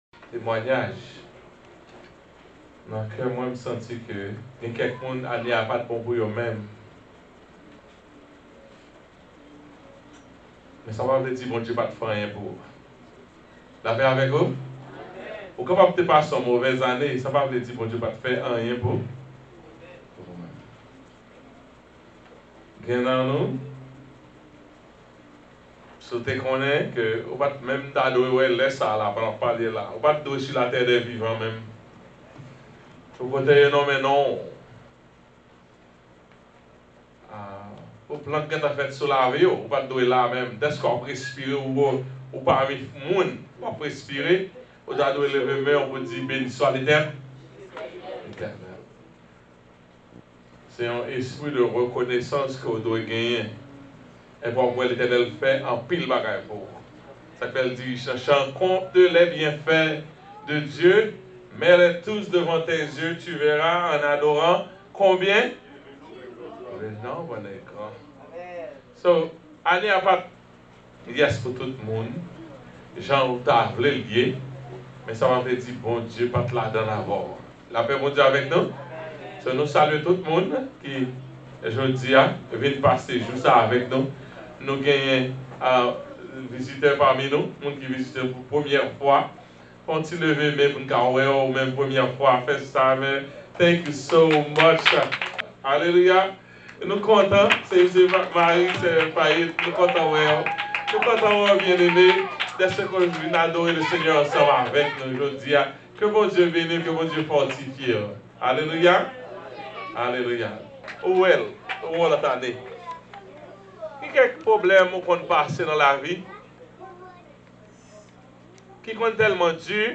NEW YEAR’S EVE SERVICE SERMON